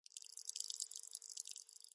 Звуки, которые издает муравей ртом и лапками